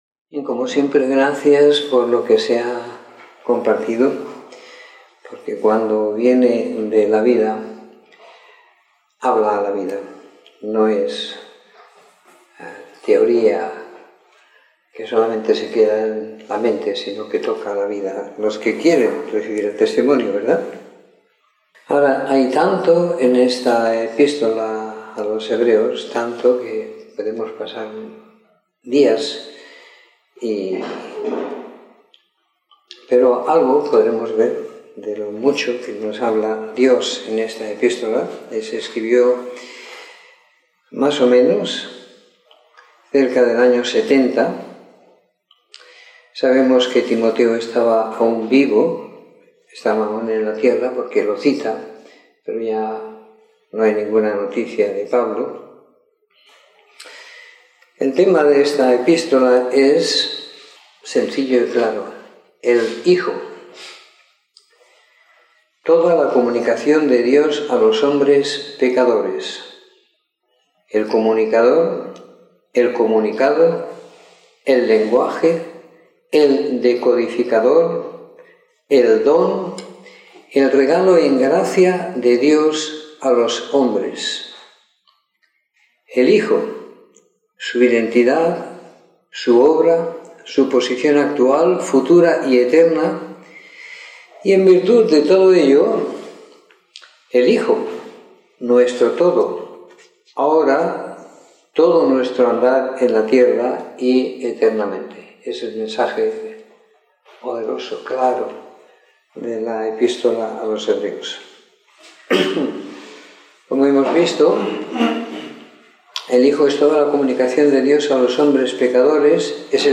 Comentario en Hebreos 1-12 - 28 de Septiembre de 2018
Comentario en la epístola de Hebreos en los caítulos 1, 2, 7, 10-12 siguiendo la lectura programada para cada semana del año que tenemos en la congregación en Sant Pere de Ribes.